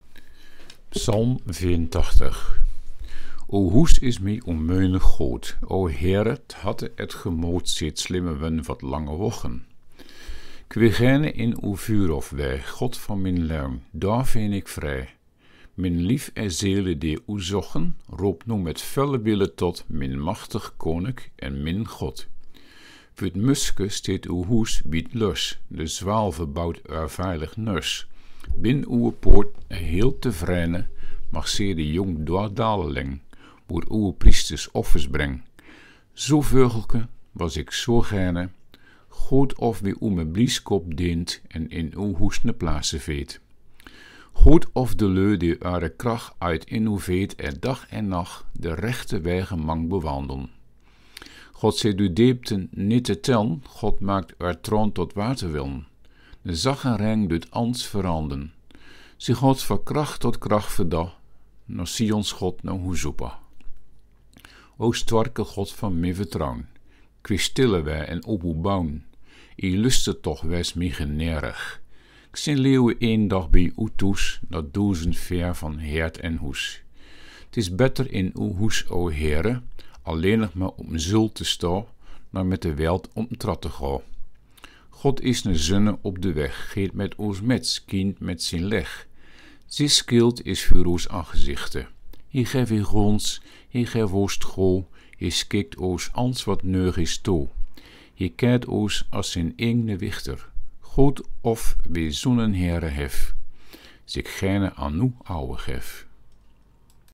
Luisterversie
De regel “noar Hoes opan” (couplet 3) klinkt in het Rijssens dialect ongeveer als: ”…noahoeszobbà…”.
“vuegelken” spreek je uit als “vuegelke” de uitgangs-n is de spellingregel, maar je hoort hem niet bij het uitspreken.